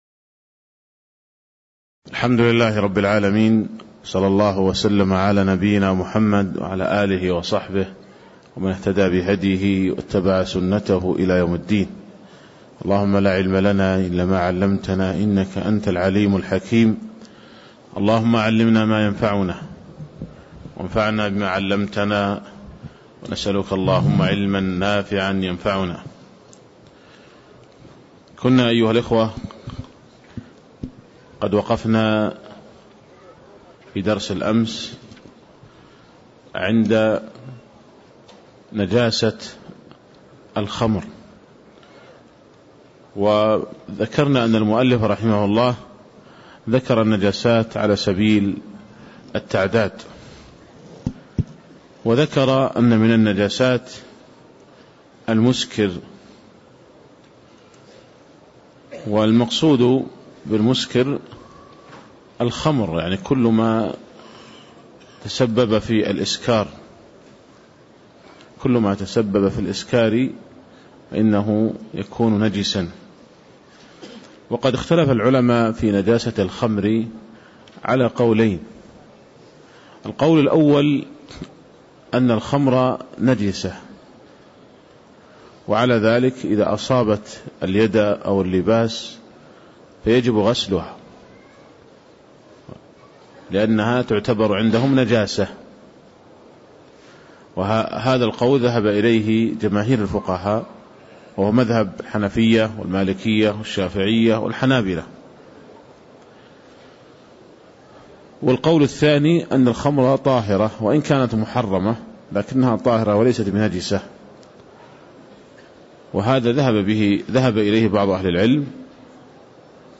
تاريخ النشر ١٢ شوال ١٤٣٦ هـ المكان: المسجد النبوي الشيخ